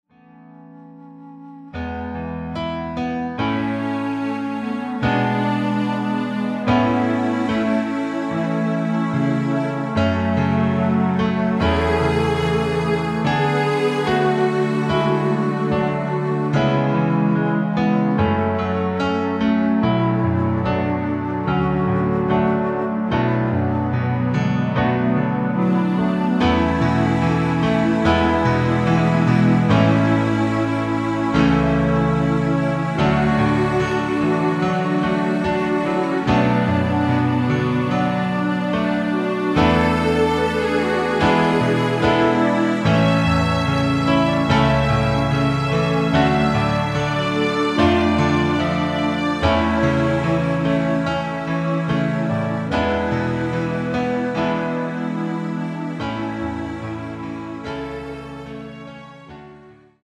피아노와 스트링만으로 편곡한 MR 입니다. (-3)내린 멜로디 포함된 MR 입니다.(미리듣기 참조)
앞부분30초, 뒷부분30초씩 편집해서 올려 드리고 있습니다.